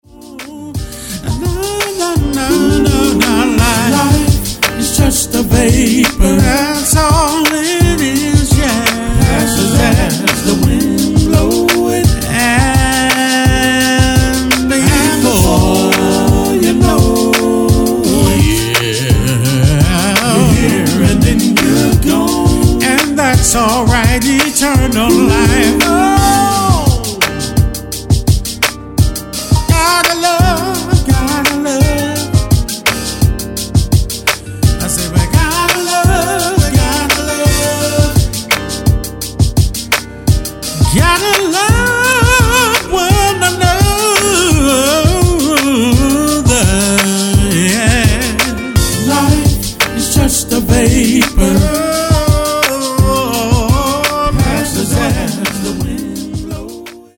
INSPIRATIONAL R&B